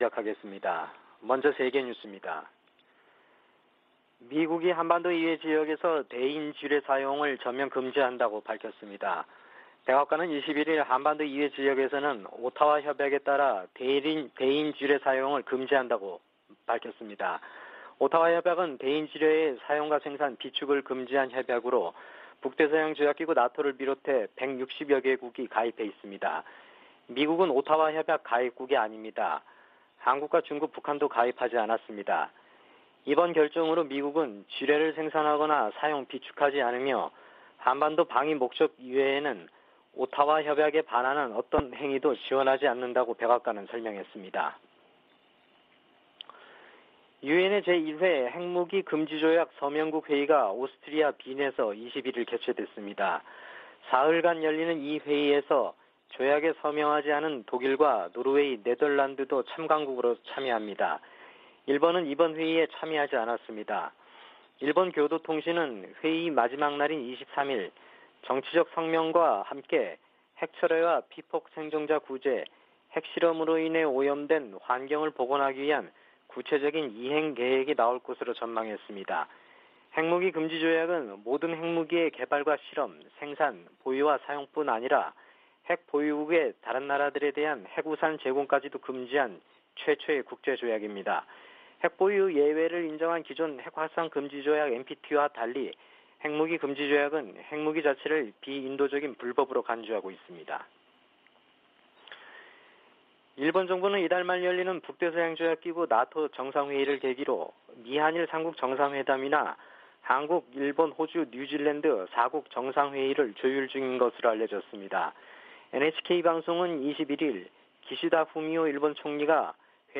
VOA 한국어 '출발 뉴스 쇼', 2022년 6월 22일 방송입니다. 한국이 21일 자체 개발 위성을 쏘아올리는데 성공해, 세계 7번째 실용급 위성 발사국이 됐습니다. 북한이 풍계리 핵실험장 4번 갱도에서 핵실험을 하기 위해서는 수개월 걸릴 것이라고 미국 핵 전문가가 전망했습니다. 북한이 과거 기관총과 박격포 탄약, 수류탄 등 약 4천만개의 탄약을 중동 국가에 판매하려던 정황이 확인됐습니다.